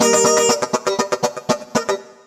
120FUNKY14.wav